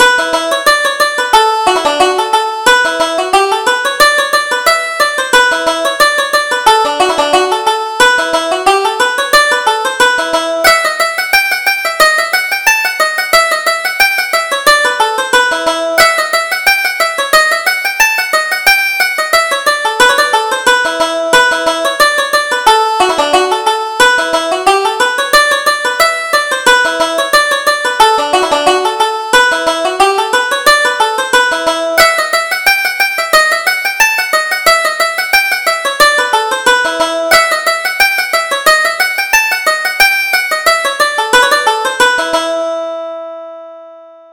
Reel: The Piper's Son